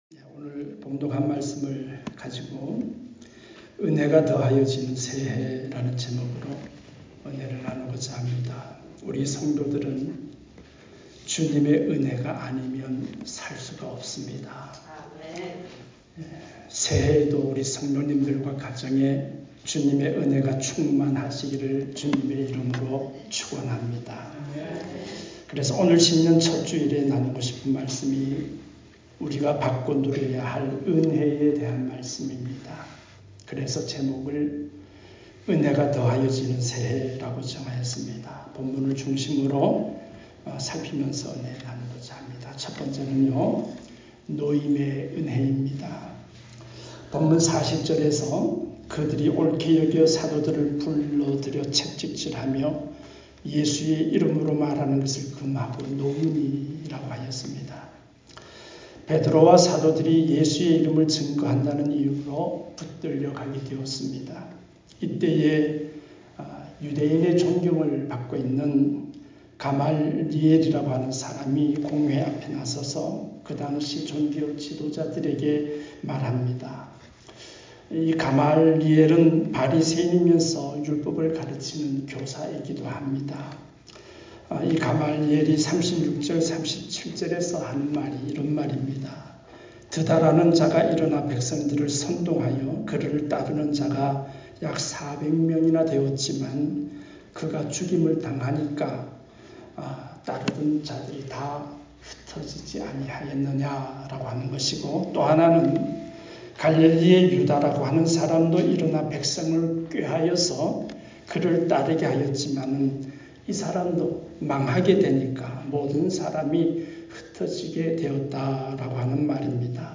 말씀